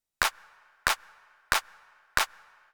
14 Clapping and Counting Basic Rhythms
Quarter notes.